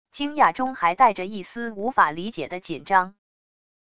惊讶中还带着一丝无法理解的紧张wav音频